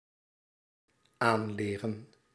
Ääntäminen
IPA: /ˈlæˌra/